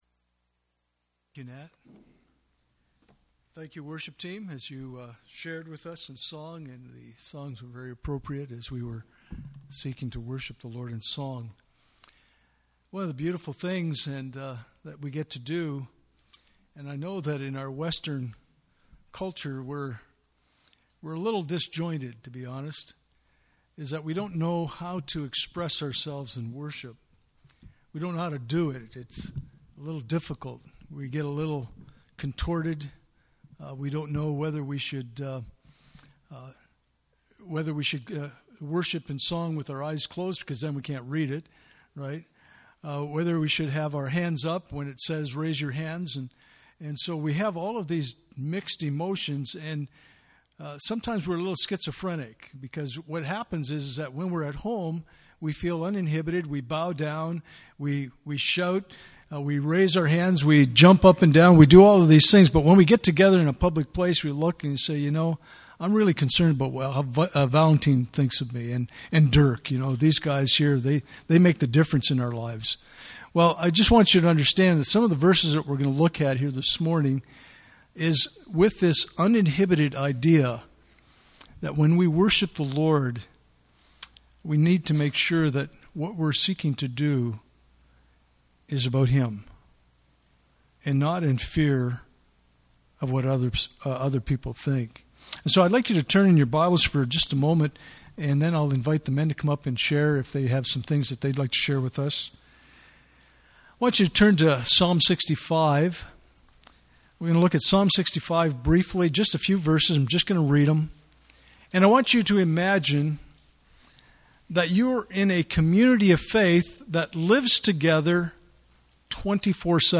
Communion Service
Passage: Psalm 134:1-3 Service Type: Sunday Morning « Being Unashamed